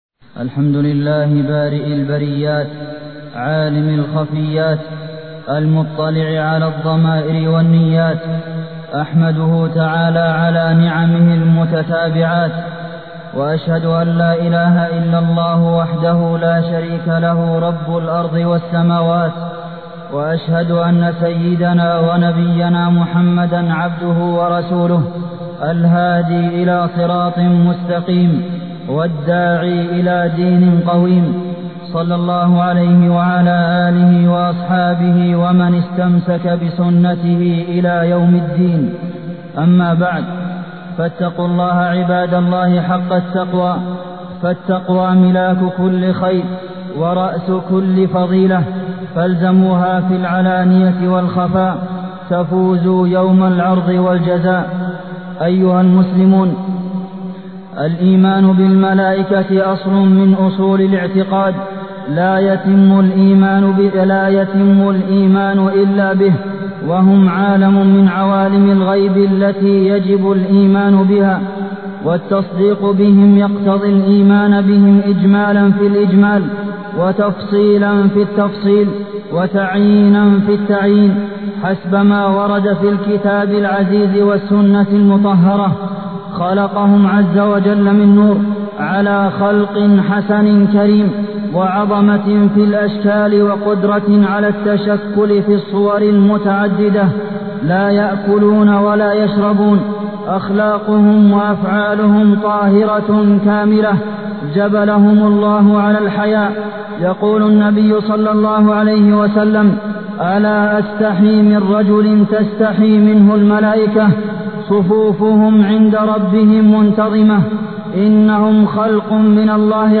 تاريخ النشر ١٨ ربيع الأول ١٤٢٠ هـ المكان: المسجد النبوي الشيخ: فضيلة الشيخ د. عبدالمحسن بن محمد القاسم فضيلة الشيخ د. عبدالمحسن بن محمد القاسم الإيمان بالملائكة